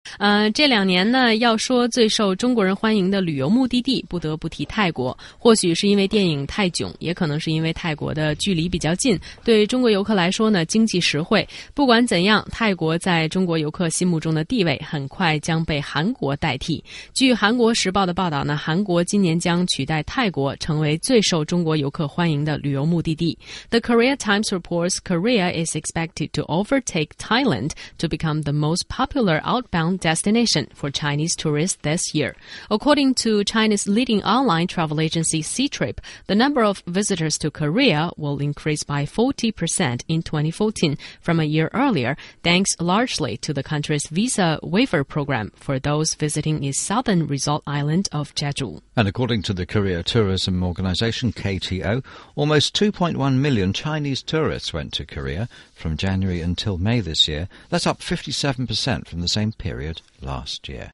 中英双语的音频，能够帮助提高英语学习者的英语听说水平，中外主持人的地道发音，是可供模仿的最好的英语学习材料,可以帮助英语学习者在轻松娱乐的氛围中逐渐提高英语学习水平。